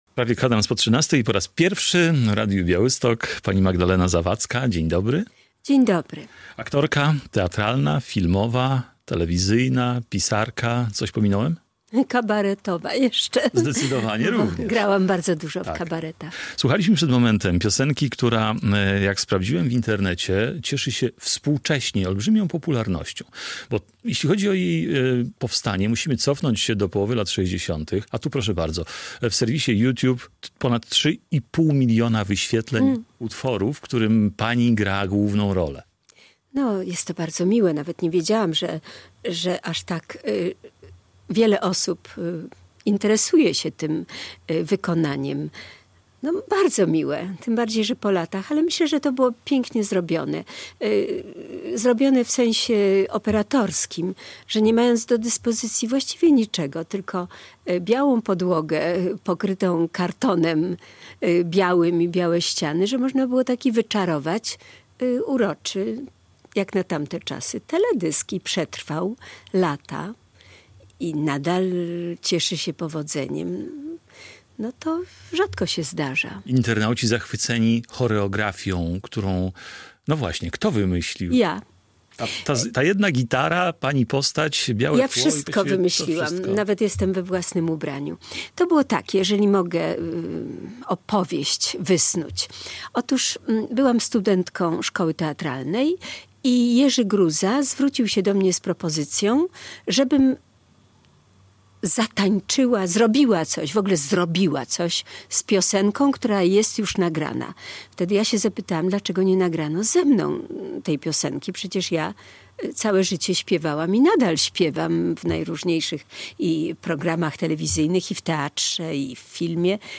Radio Białystok | Gość | Magdalena Zawadzka - aktorka filmowa i teatralna